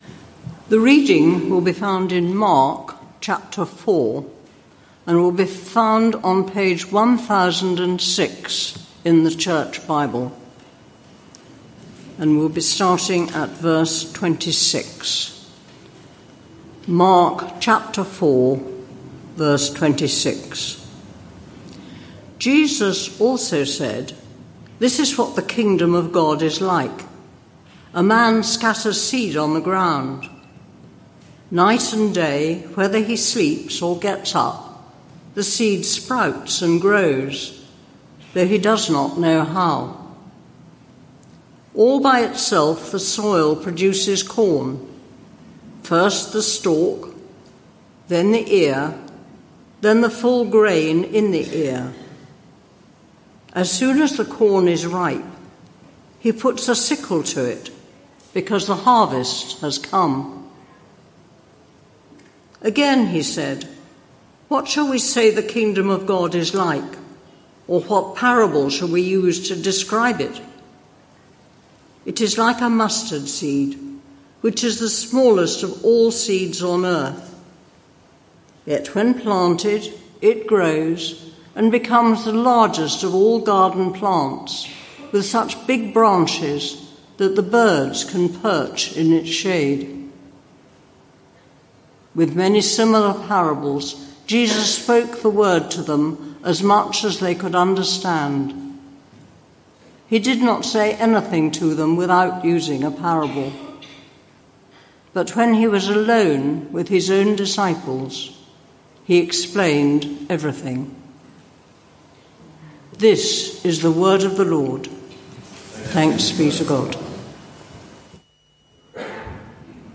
This sermon